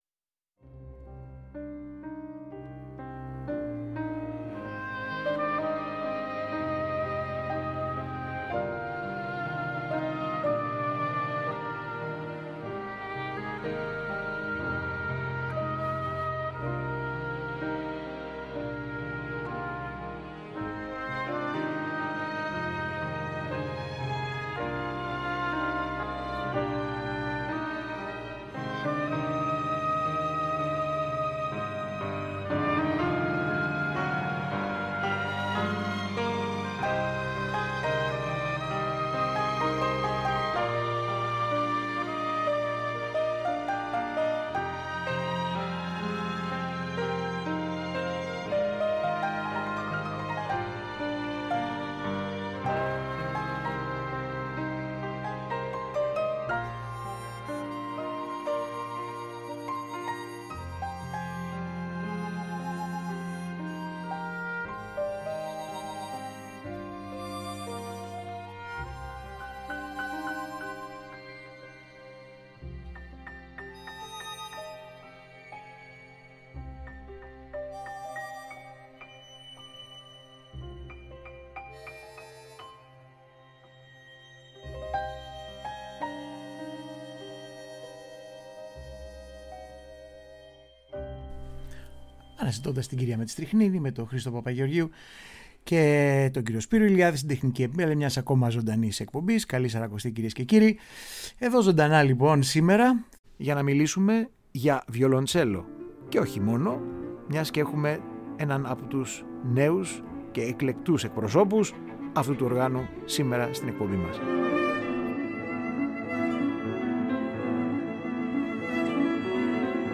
Mια ωριαία ζωντανή ραδιοφωνική συνέντευξη